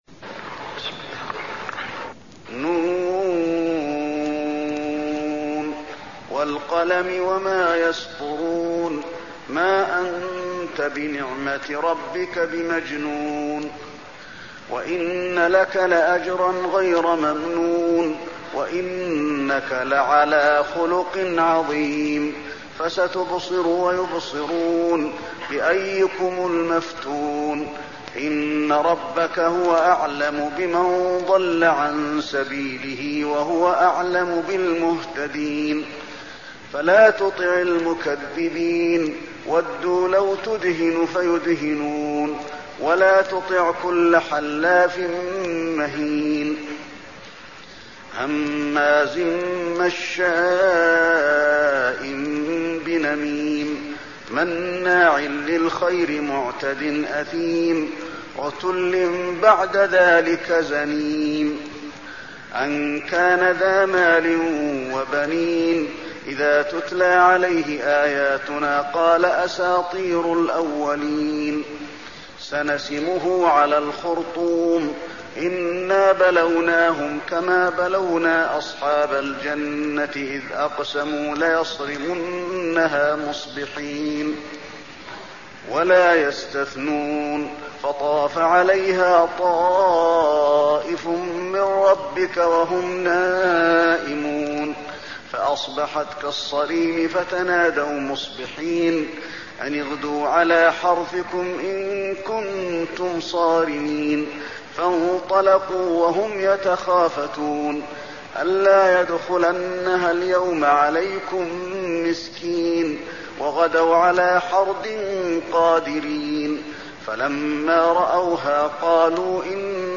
المكان: المسجد النبوي القلم